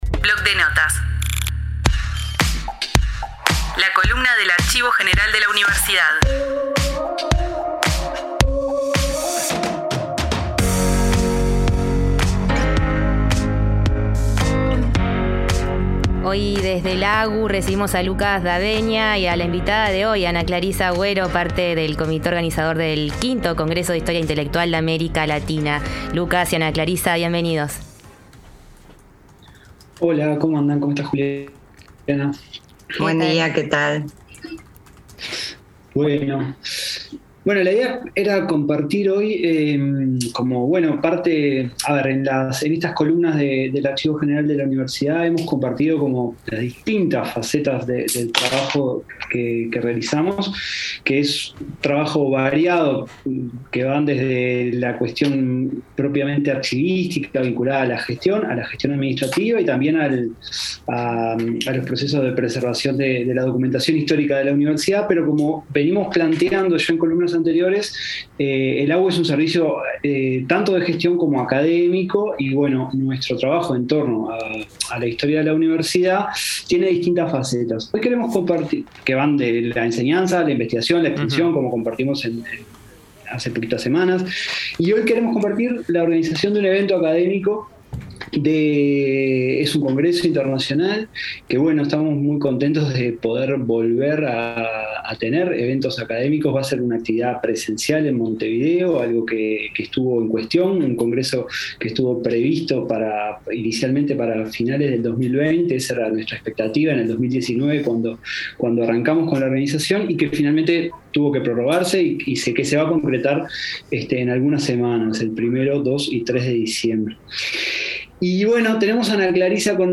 columna de deportes